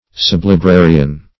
Sublibrarian \Sub`li*bra"ri*an\, n. An under or assistant librarian.